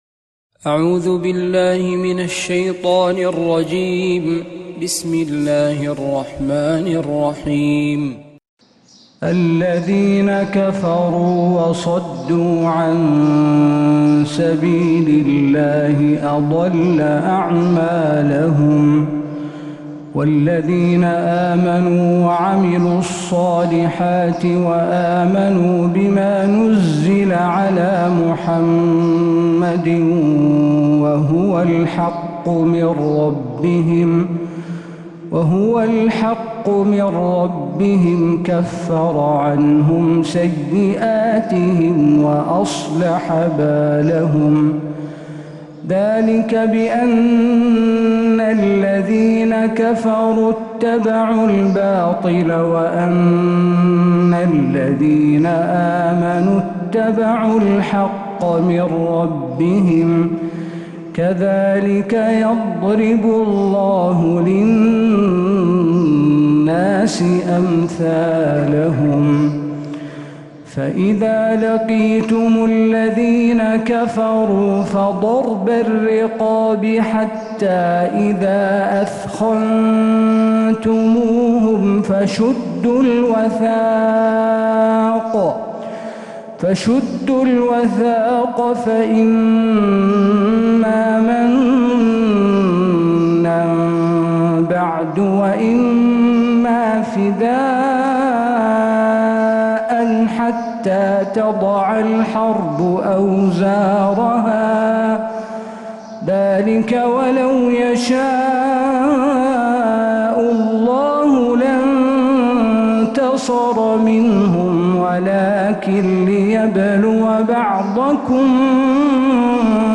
سورة محمد كاملة من فجريات الحرم النبوي